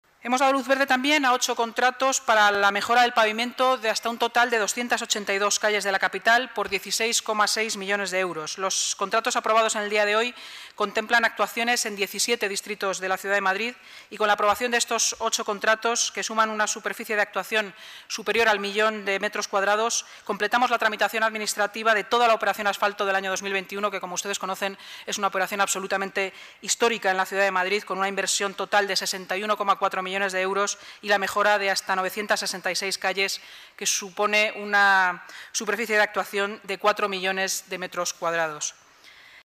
Nueva ventana:Inmaculada Sanz, portavoz municipal explica los últimos contratos de la Operación Asfalto 2021